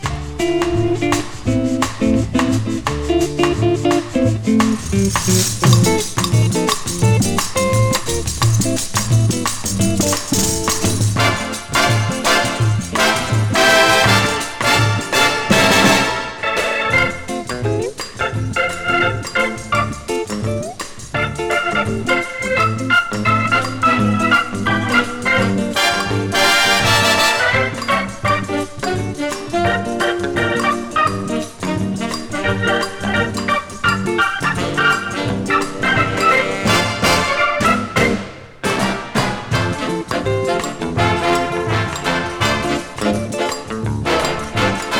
Jazz, Rock, Pop, Lounge　USA　12inchレコード　33rpm　Mono